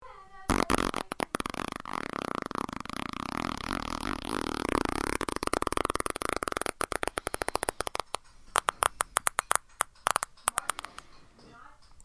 Sound Effects » Zipper
描述：Zipper.
标签： zip zipping zipper
声道立体声